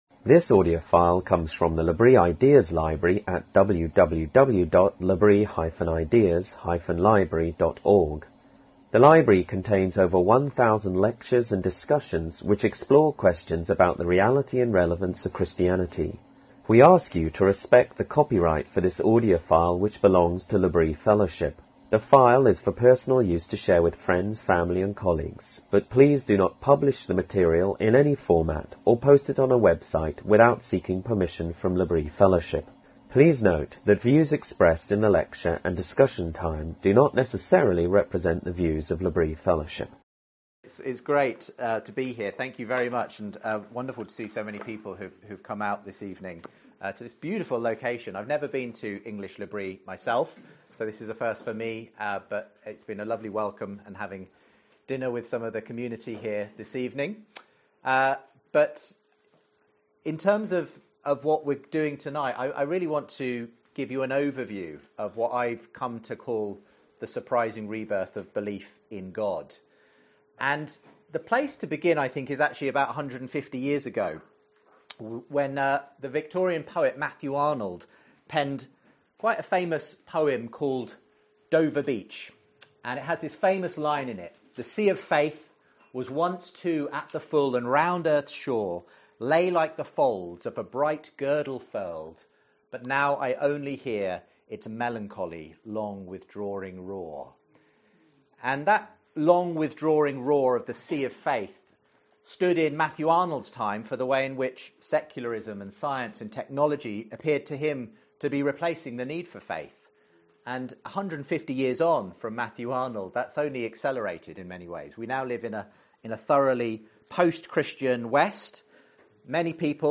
In this lecture given at the English L’Abri